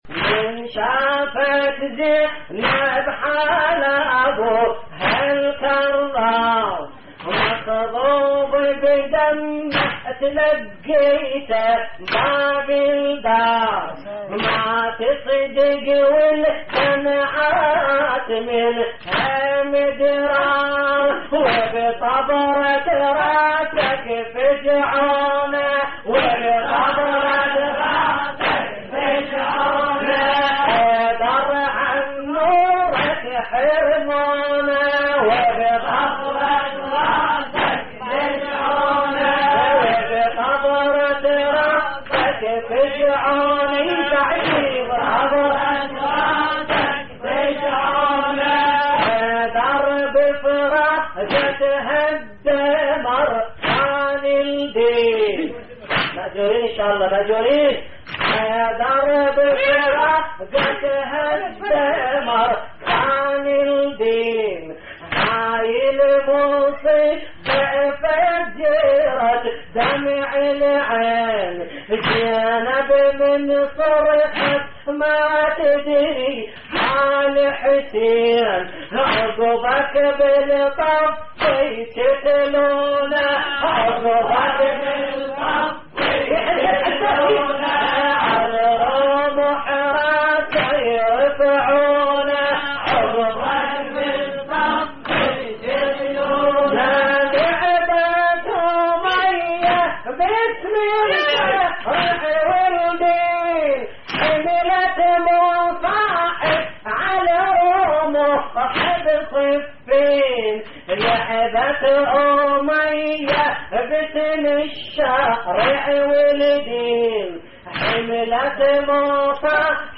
موقع يا حسين : اللطميات الحسينية من شافت زينب حال أبوها الكرار مخضوب بدمه - هوسات لحفظ الملف في مجلد خاص اضغط بالزر الأيمن هنا ثم اختر (حفظ الهدف باسم - Save Target As) واختر المكان المناسب